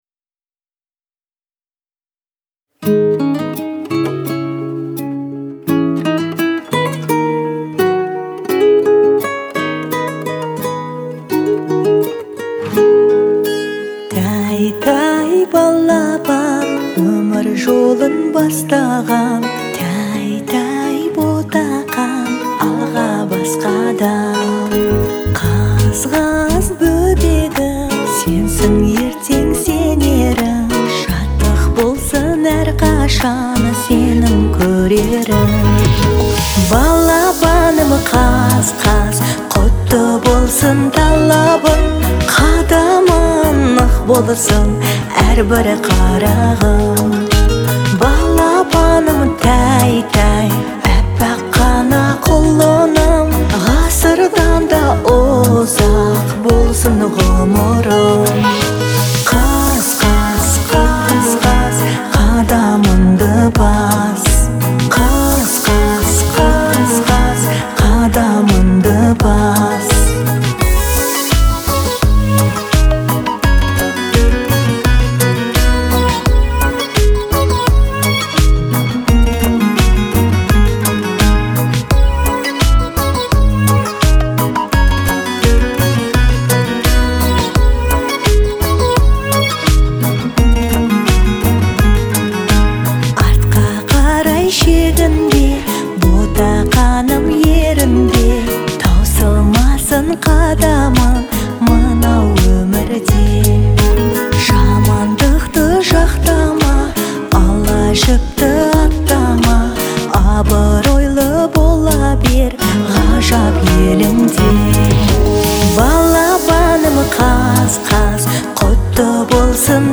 это яркая и мелодичная песня в жанре казахской поп-музыки